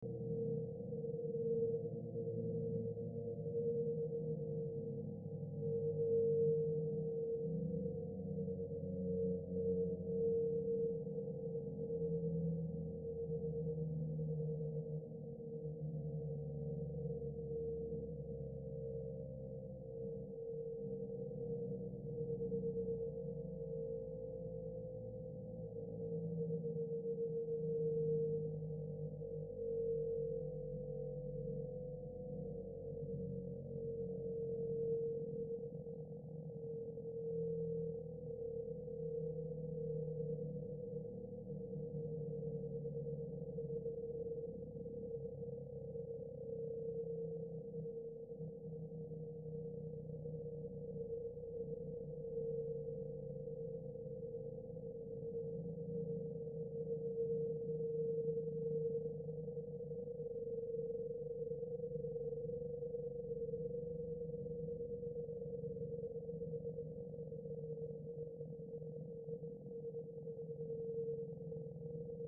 Scary Ambience 3 Sound Effect Download: Instant Soundboard Button